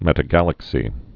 (mĕtə-gălək-sē)